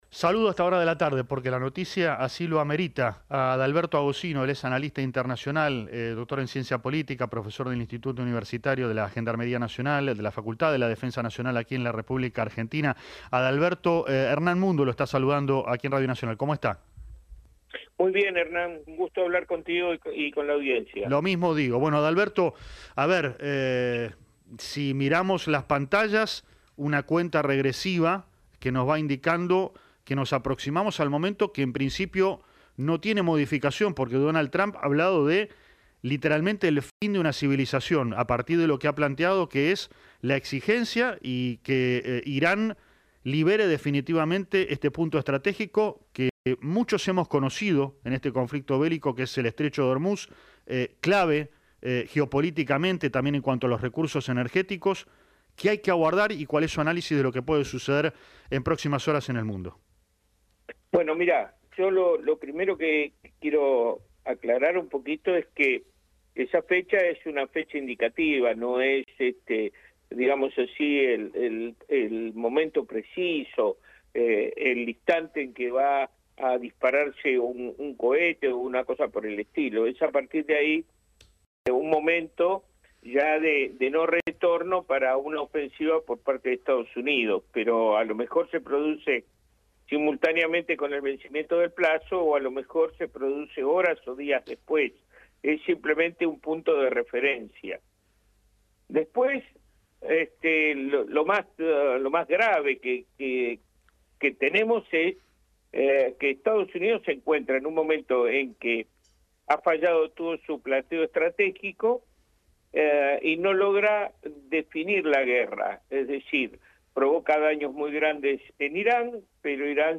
PANORAMA NACIONAL Entrevista